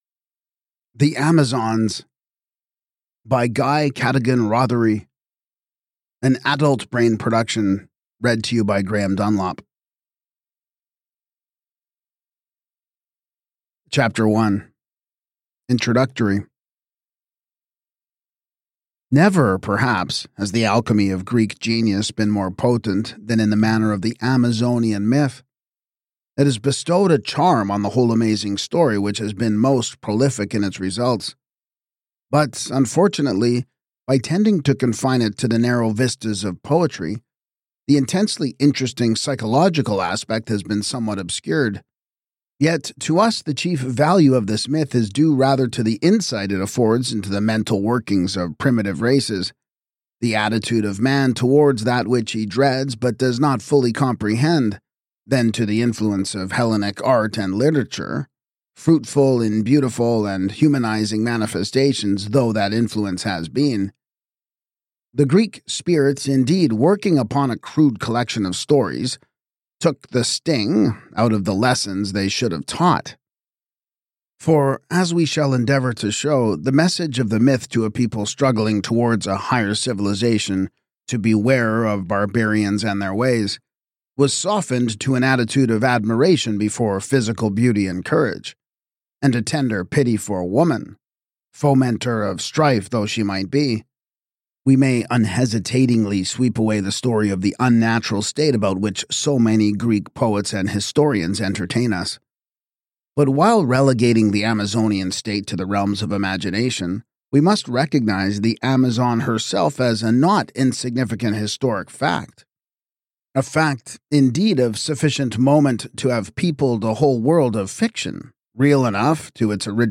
This audiobook is equal parts scholarship and storytelling.